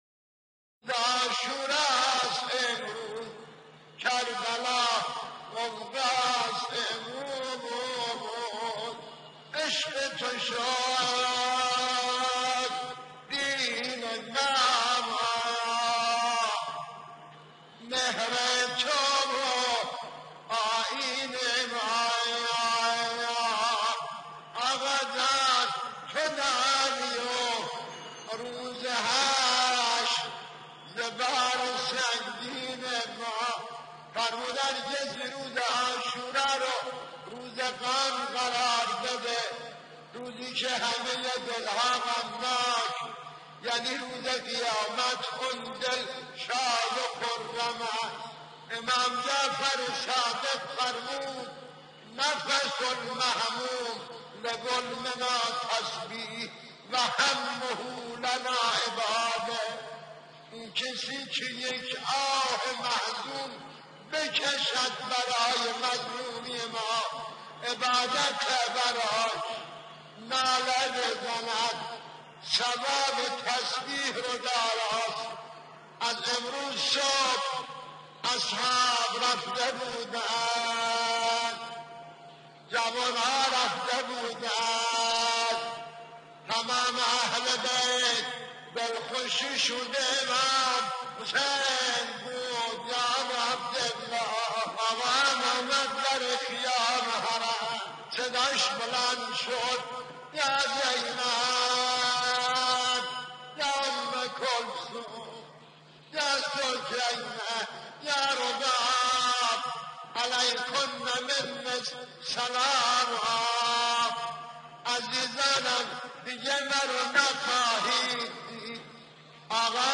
خیمه گاه - هیئت مجازی حبیب بن مظاهر(ع) - روضه کربلا